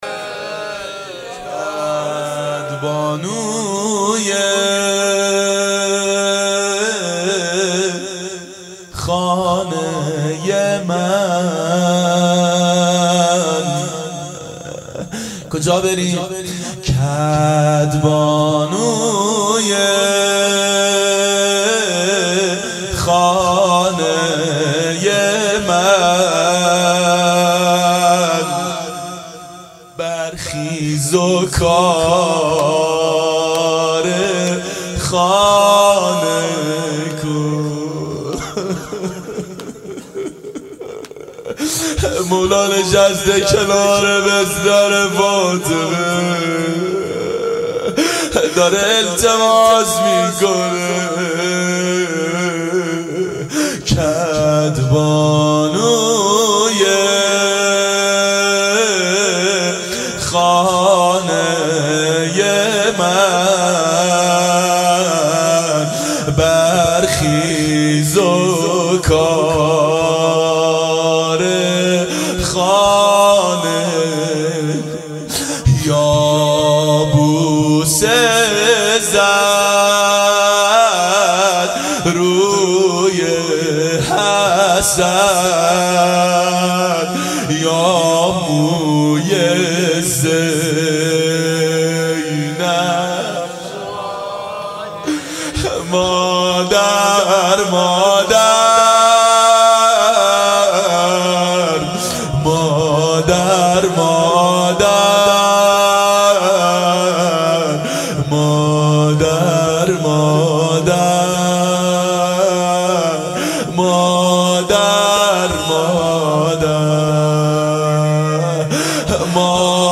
روضه حضرت زهرا سلام الله علیها
• دهه اول صفر سال 1390 هیئت شیفتگان حضرت رقیه س شب سوم (شام غریبان)
03-روضه-حضرت-زهرا-س.mp3